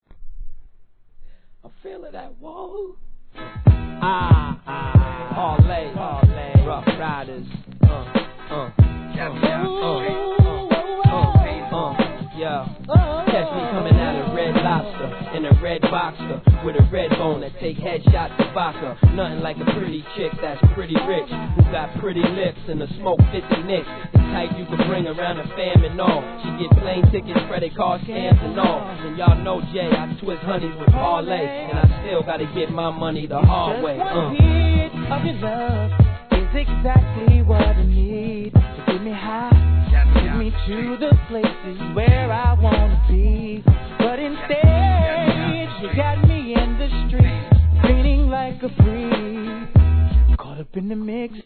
HIP HOP/R&B
ネタ感あるトラックにキャッチーなフック